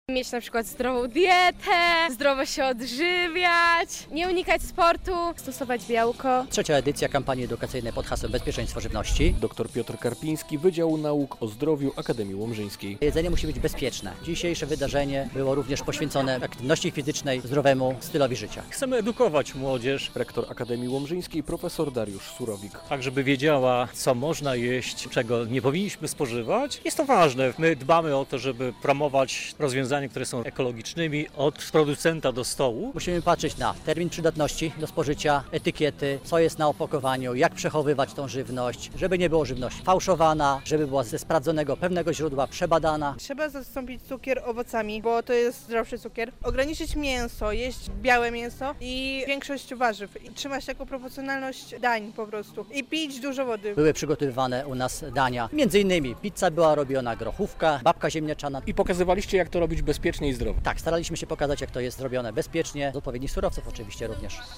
Piknik przy Akademii Łomżyńskiej, 6.06.2025, fot.
relacja